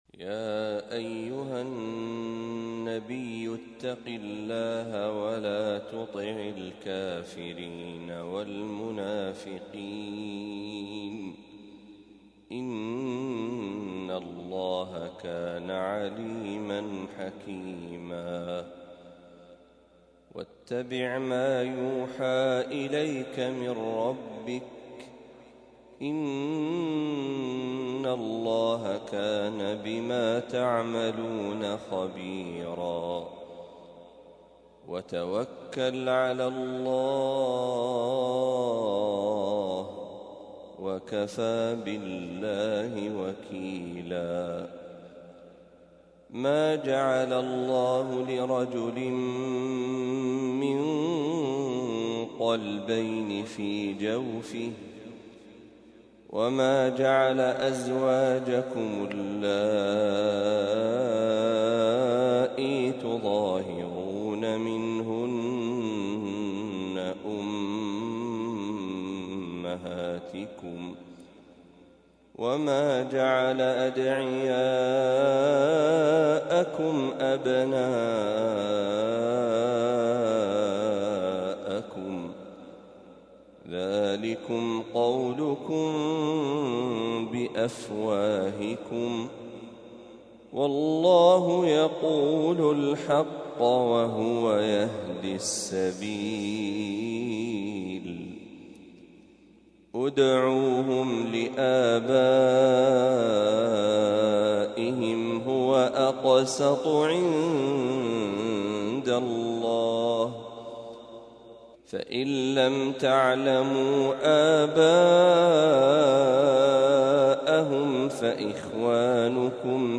سورة الأحزاب كاملة | من فجريات شهر صفر ١٤٤٦هـ > 1446هـ > تلاوات الشيخ محمد برهجي > المزيد - تلاوات الحرمين